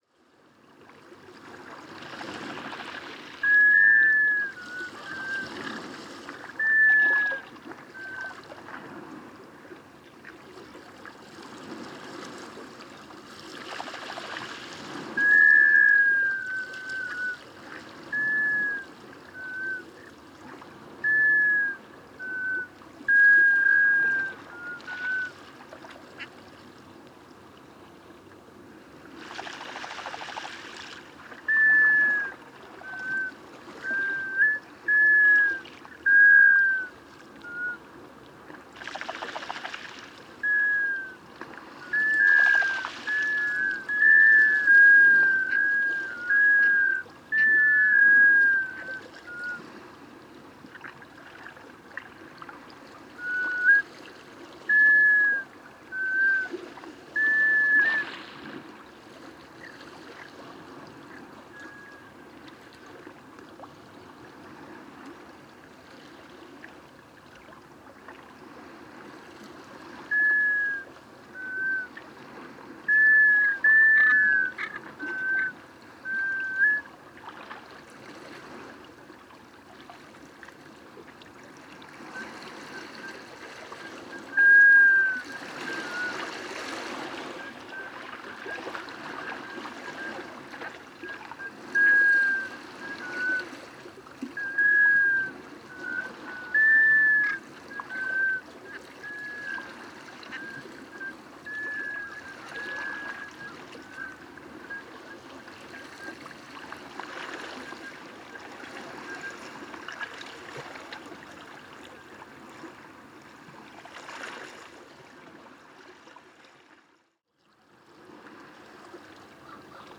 Macreuse à bec jaune – Melanitta americana
Chant et ‘Grunt’ Petit groupe d’individus en migration se reposant dans la baie. Parc national du Bic – Secteur Pointe-aux-Épinettes, Rimouski-Neigette, QC 48°21’38.2″N 68°46’50.6″W. 9 mai 2019. 8h30.